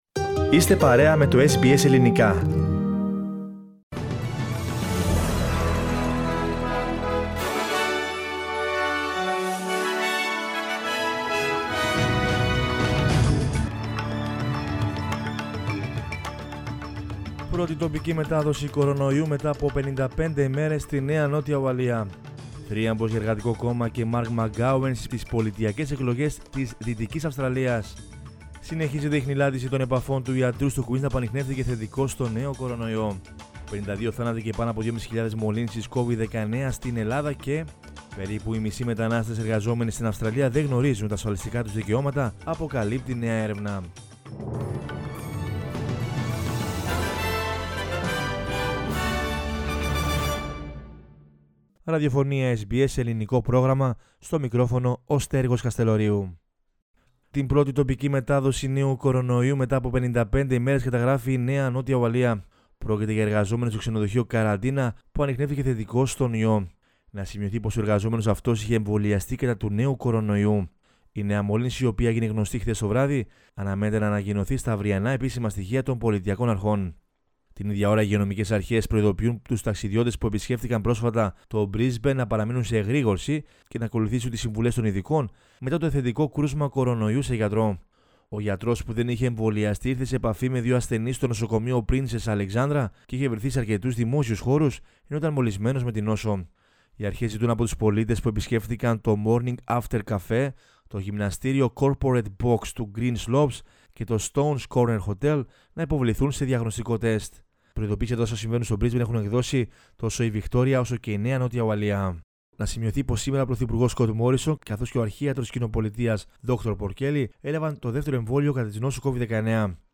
News in Greek from Australia, Greece, Cyprus and the world is the news bulletin of Sunday 14 March 2021.